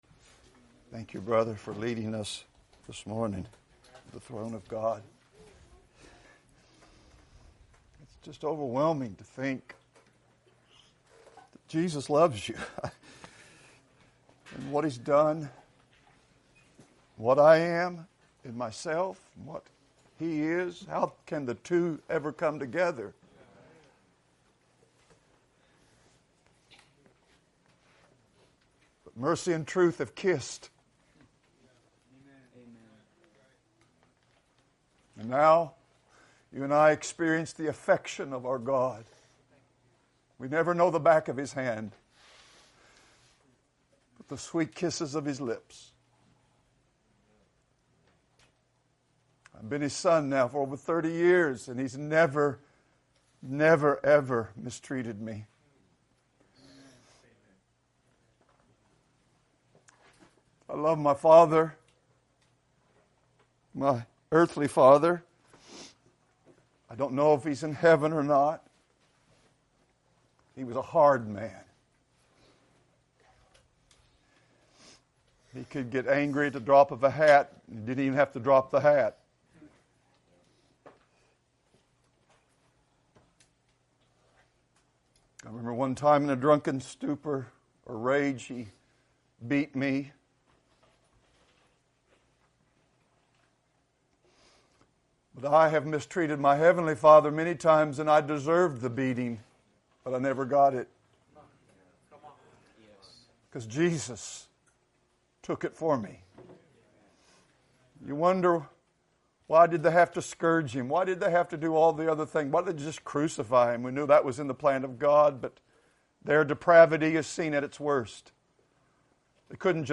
2020 Men's Retreat | Series: Desperate Dependency: Living by Grace | Abiding in Christ is not automatic, it is a command to be obeyed.
Full Sermons What does it mean to abide in Christ?